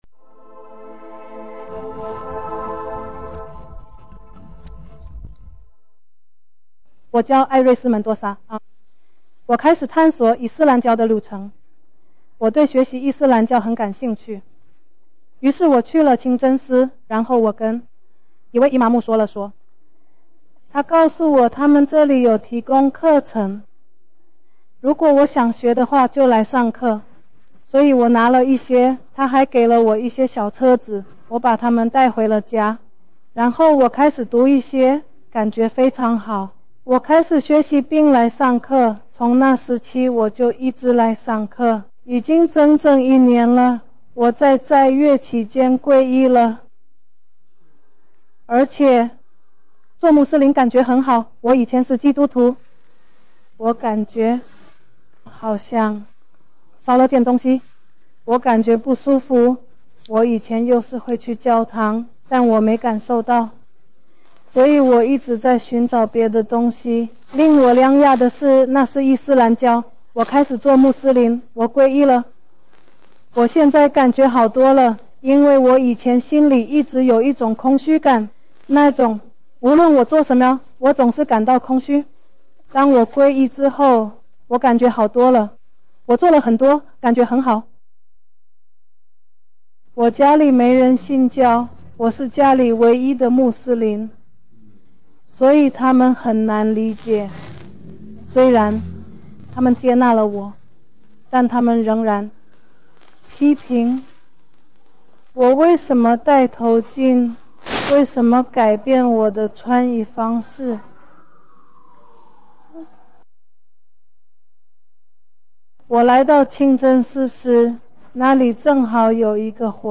视频 新穆斯林故事 女性